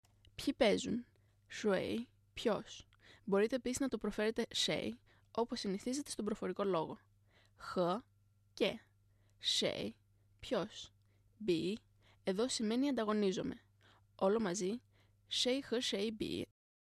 谁= ποιος; Μπορείτε επίσης να το προφέρετε 谁 shéi, όπως συνηθίζεται στον προφορικό λόγο.